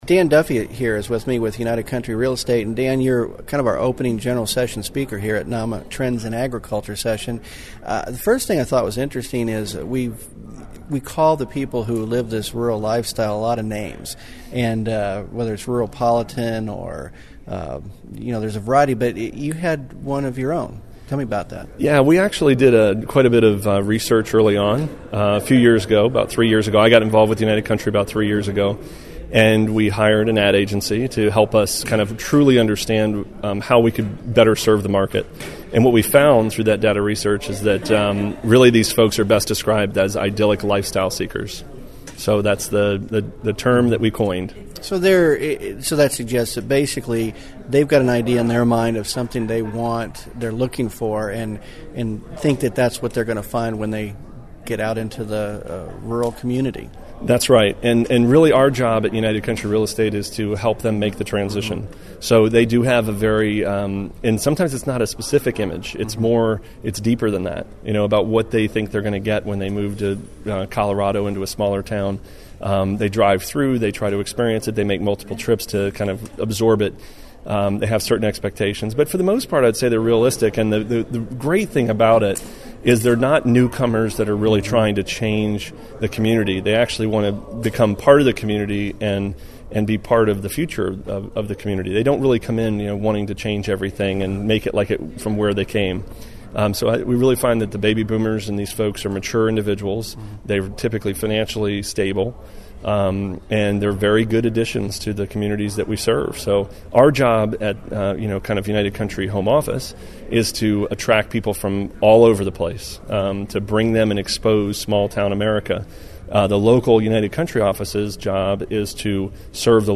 Interview (mp3)